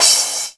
• Short Reverb Crash Single Hit E Key 06.wav
Royality free crash cymbal single hit tuned to the E note. Loudest frequency: 6482Hz
short-reverb-crash-single-hit-e-key-06-DNg.wav